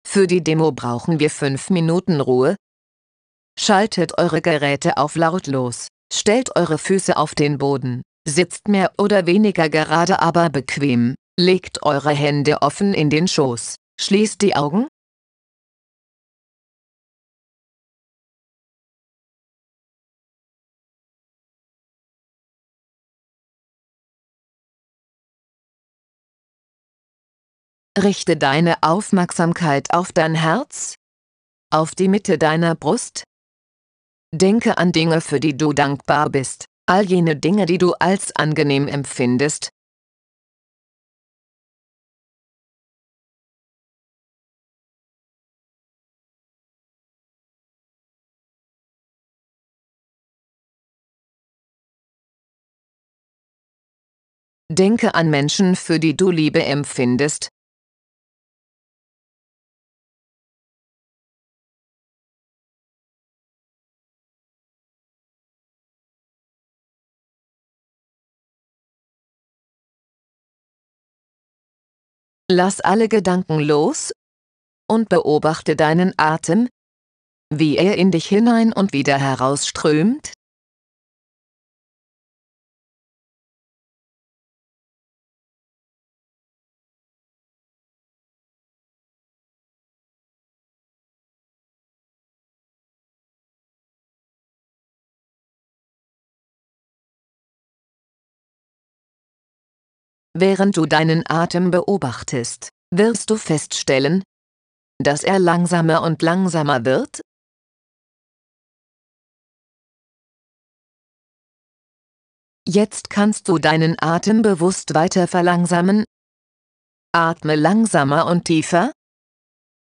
Geleitete Audiodemo